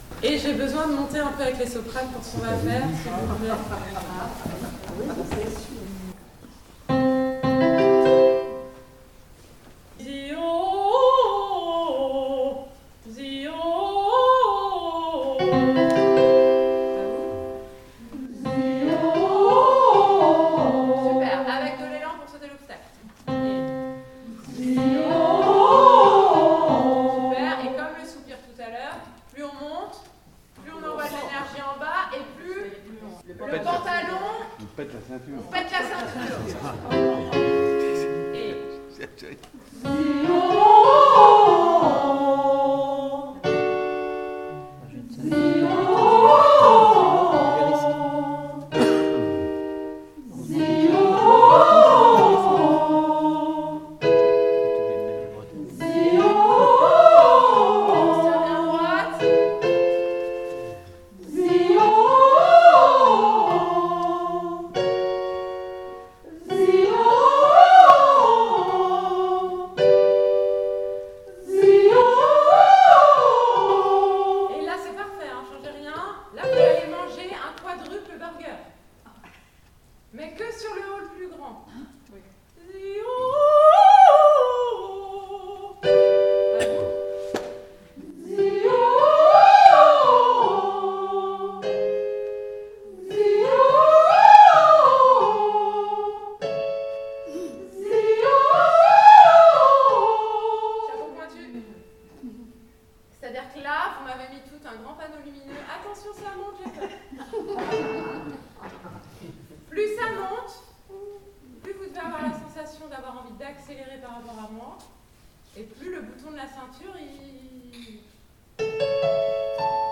Echauffement vocal
Ces enregistrements au format MP3 ont été réalisés lors de la répétiton du 22 septembre 2025.
Bonus pour voix S (soprano) Echauffement 3 - La licorne (voix sopranes)
3 - LA LICORNE -  Pour les chanteurs, un exercice efficace consiste à pratiquer des vocalises, soit des gammes ascendantes et descendantes, en utilisant des voyelles telles que "A," "E" et "O".